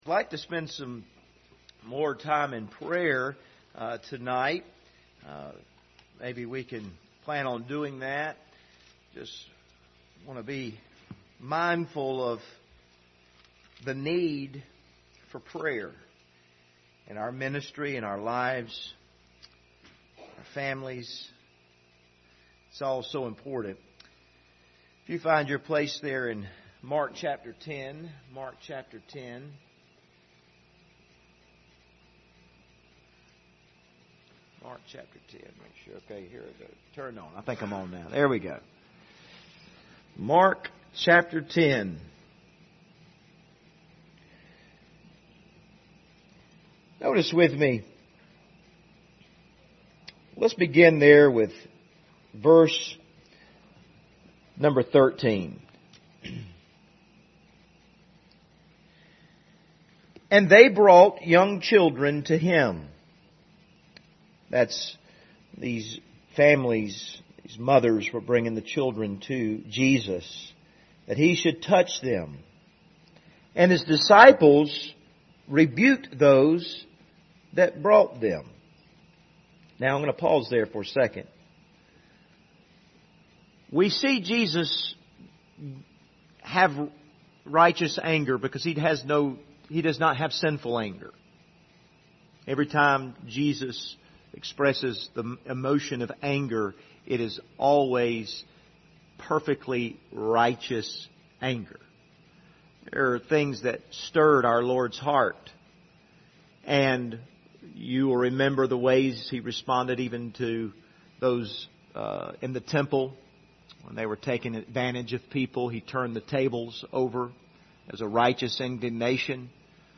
Passage: Mark 10 Service Type: Wednesday Evening View this video on Facebook « Emotionally Healthy Discipleship